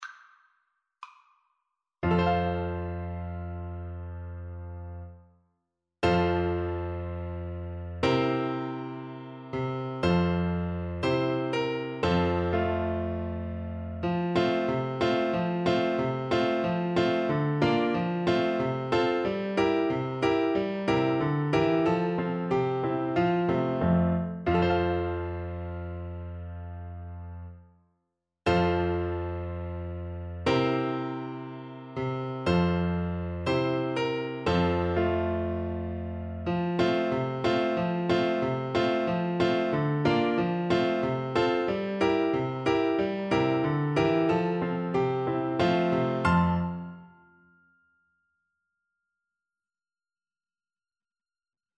Slow =c.60
2/2 (View more 2/2 Music)